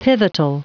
Prononciation du mot pivotal en anglais (fichier audio)